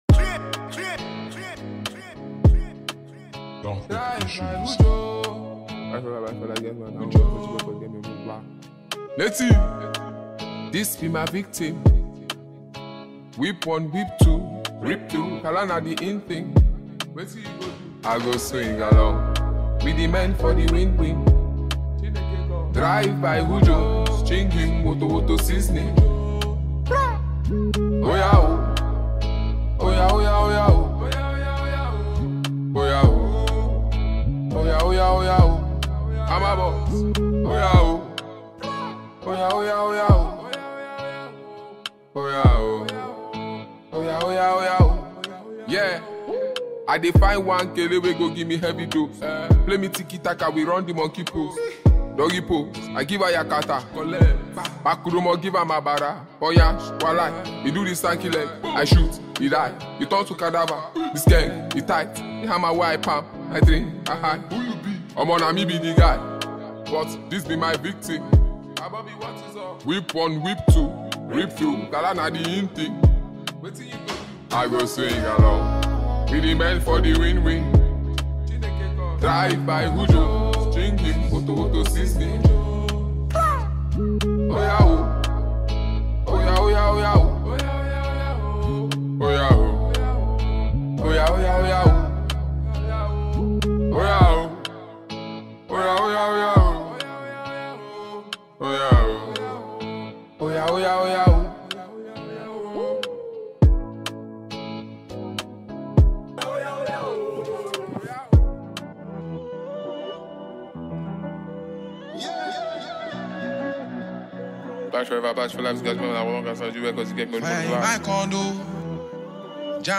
With its infectious beats and catchy melodies